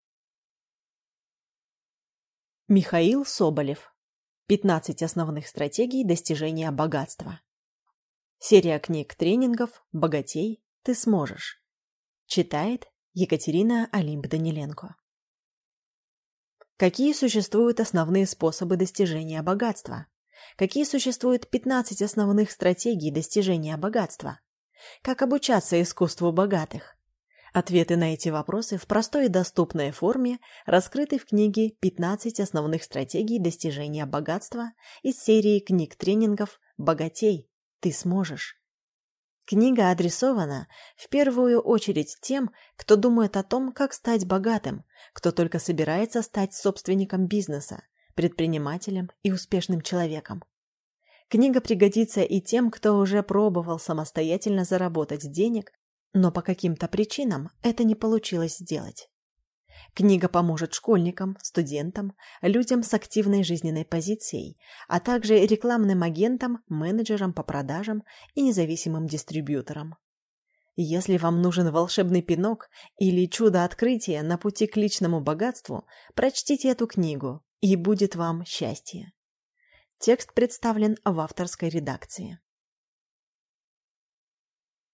Аудиокнига 15 основных стратегий достижения богатства | Библиотека аудиокниг